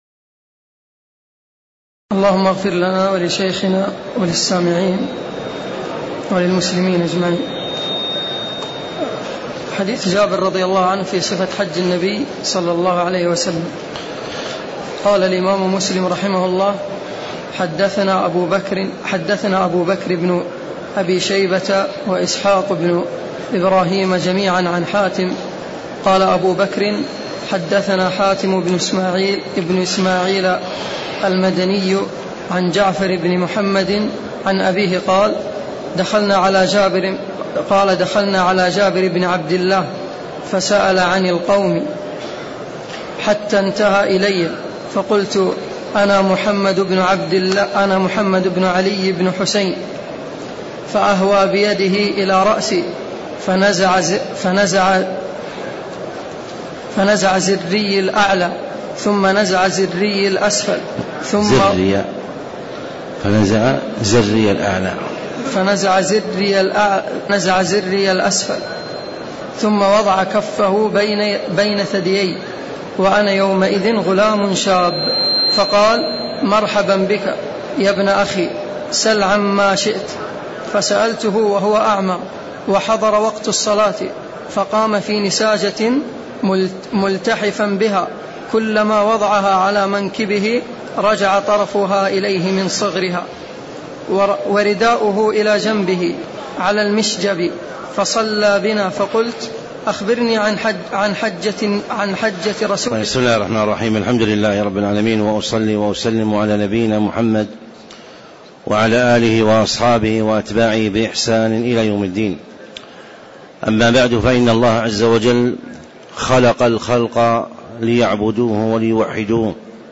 تاريخ النشر ٦ ذو القعدة ١٤٣٧ المكان: المسجد النبوي الشيخ